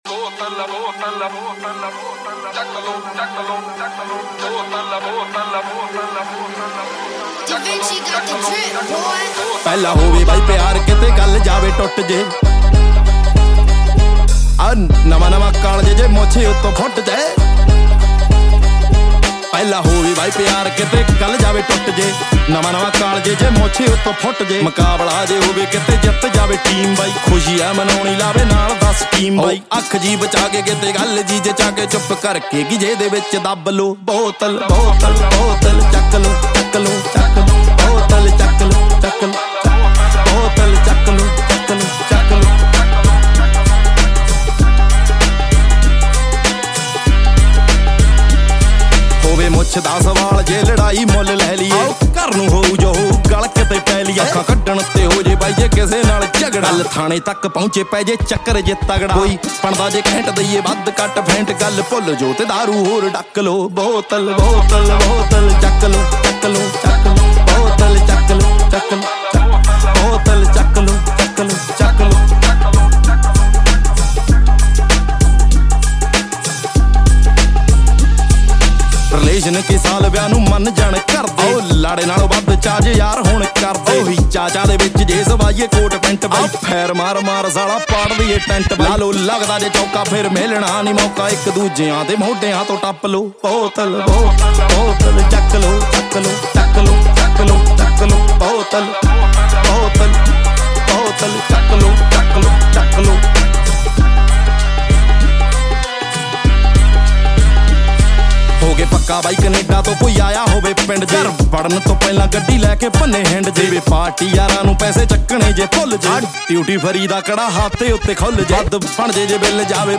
Punjabi Bhangra